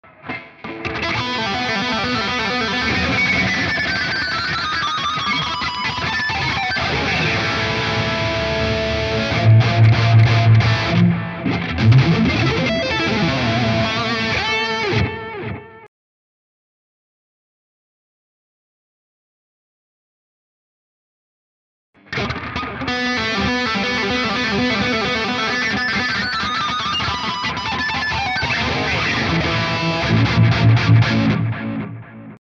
Le da un toque especial a tus riffs y solos. Requisitos: - High Gain. - Pastilla humbucker en el puente.